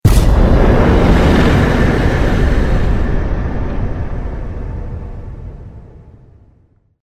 Elden Ring Enemy Felled Sound Effect Free Download